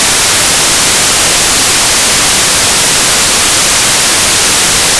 noise_22050.wav